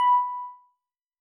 Techmino / media / sample / bell / 27.ogg
添加三个简单乐器采样包并加载（之后用于替换部分音效）